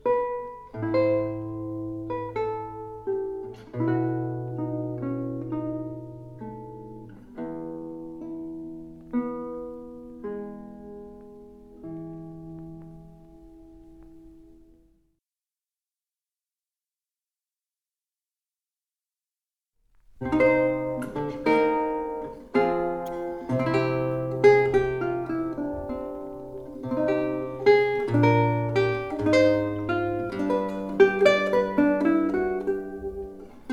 lute